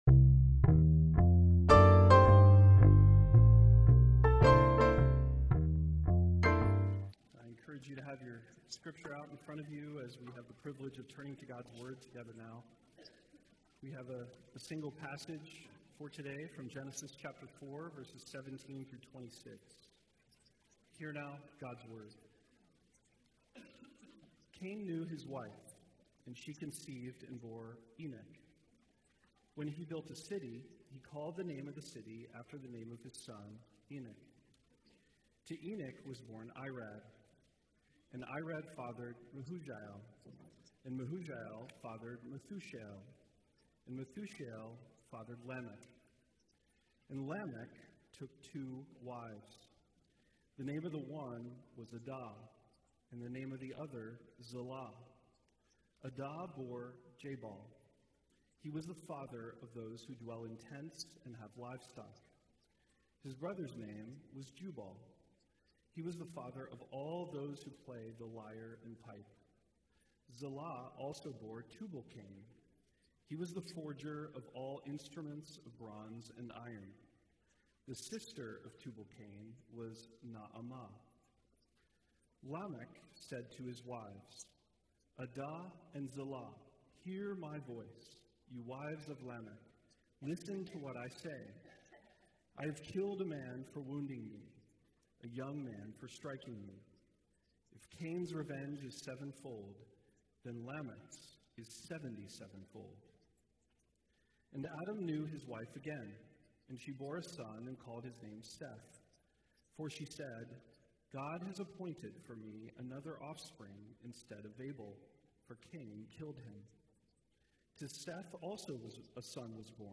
Passage: Genesis 4:17-26 Service Type: Sunday Worship